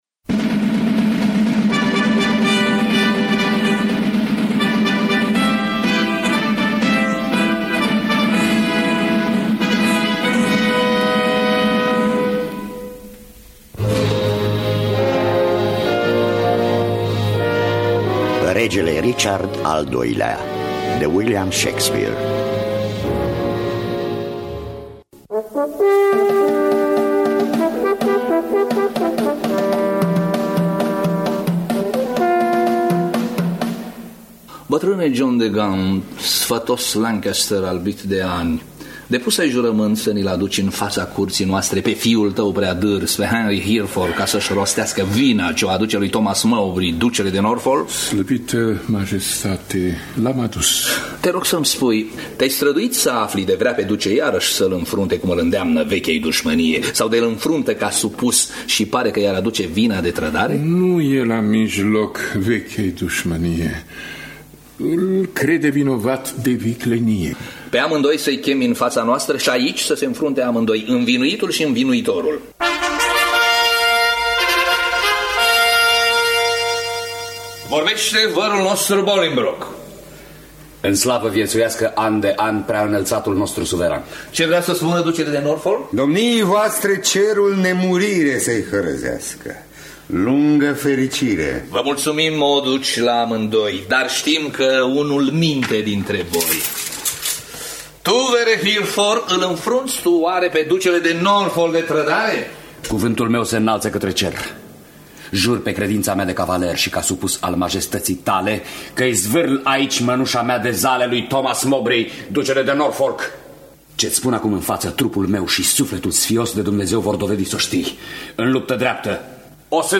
William Shakespeare – Regele Richard Al II-lea (Richard Al II-lea) (1995) – Teatru Radiofonic Online
Traducerea şi adaptarea radiofonică de Dan Amedeo Lăzărescu.